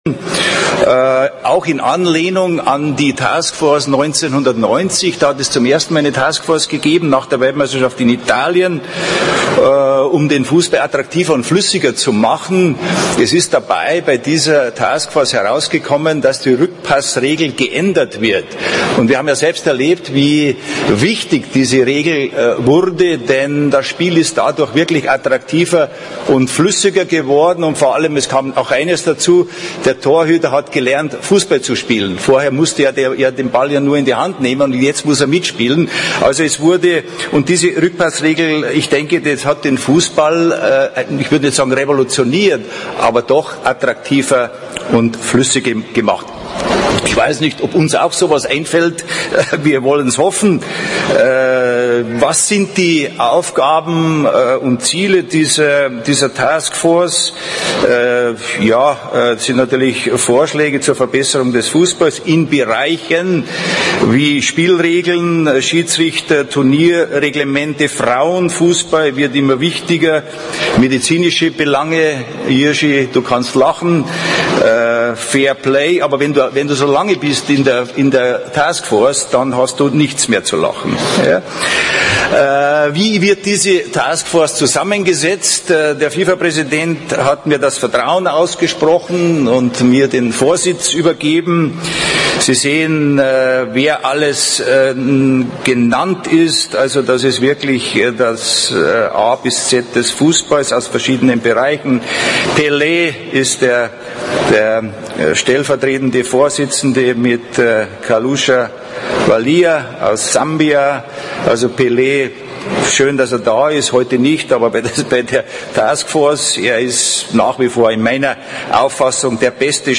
Am Ende, als Blatter ihn offiziell zum Exko-Mitglied ernannte, passierte dies: : Franz Beckenbauer, scheidendes FIFA-Exekutivmitglied, hat in seiner Eigenschaft als Chef der FIFA Task Force Football 2014 das gesagt: : (Die ersten paar Sekunden fehlen. Gegen Ende, bei ca. 11:00 habe ich mal kurz auf die Übersetzungen geschaltet.
beckenbauer-fifa-kongress.mp3